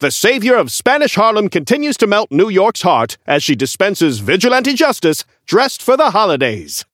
Newscaster voice line - The savior of Spanish Harlem continues to melt New York's heart, as she dispenses vigilante justice dressed for the holidays!
Newscaster_seasonal_tengu_unlock_01.mp3